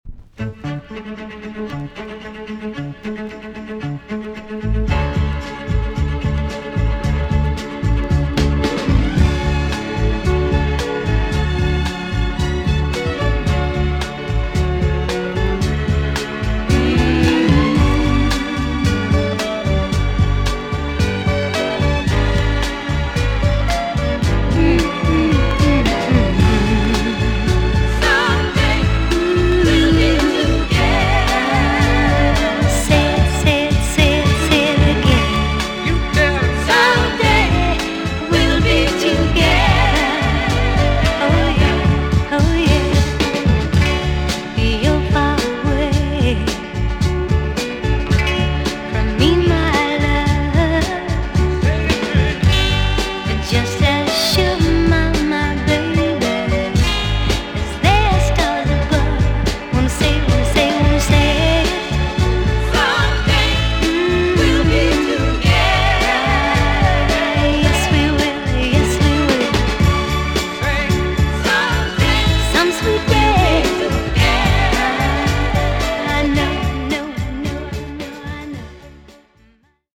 EX-音はキレイです。
1968 , RARE , NICE SOUL TUNE!!